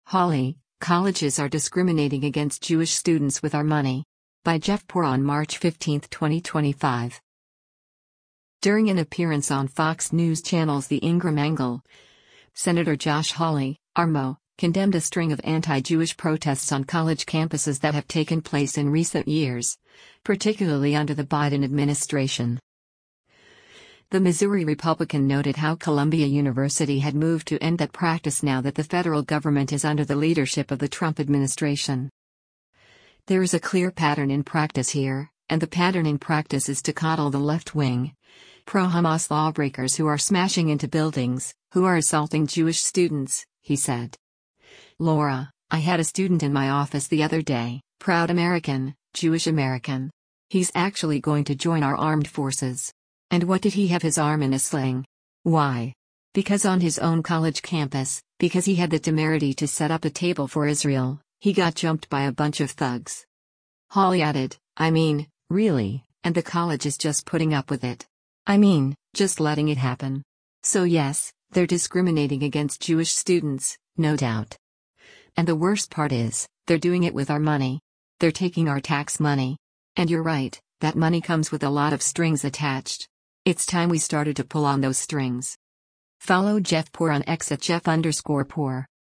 During an appearance on Fox News Channel’s “The Ingraham Angle,” Sen. Josh Hawley (R-MO) condemned a string of anti-Jewish protests on college campuses that have taken place in recent years, particularly under the Biden administration.